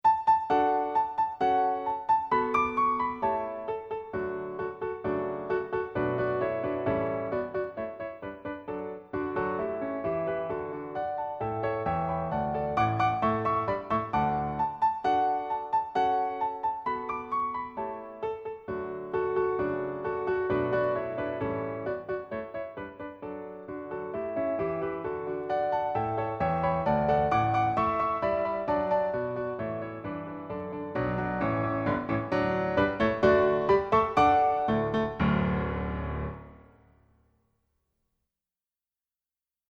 Composer: Latin American Folk Song
Voicing: Unison|2-Part